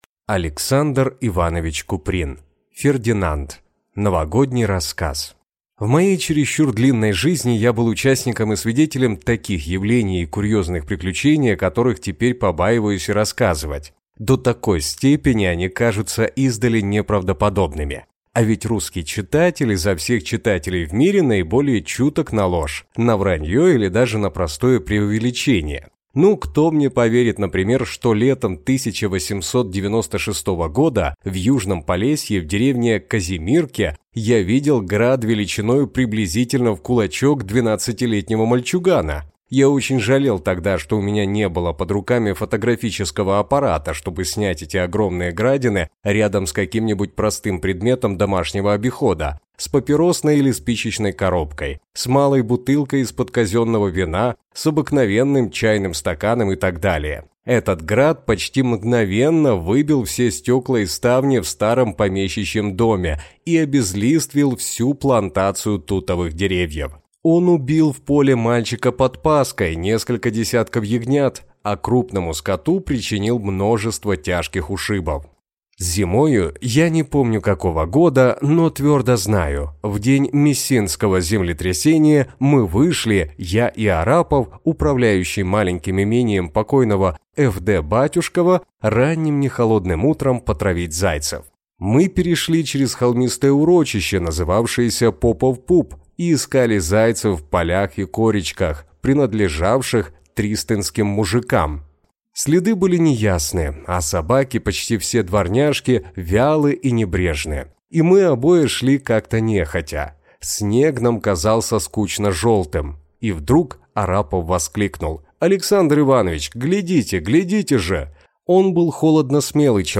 Аудиокнига Фердинанд | Библиотека аудиокниг